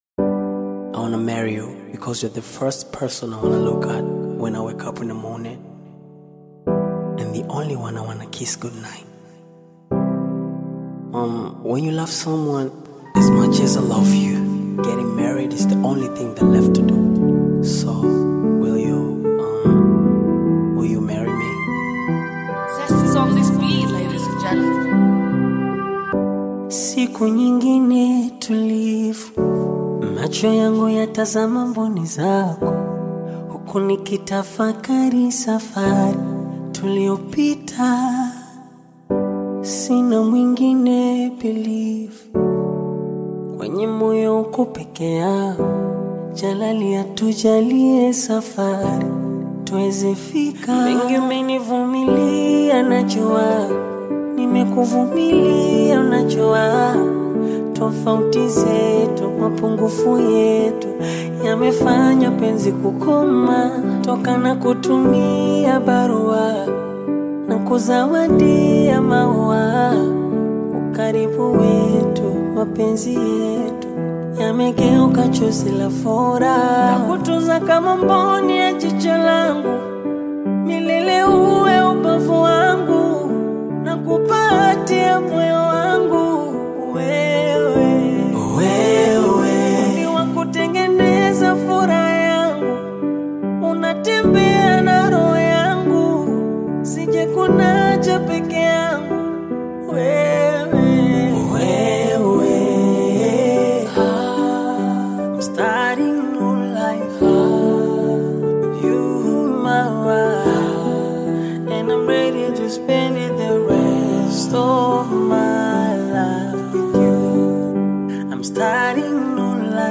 romantic ballad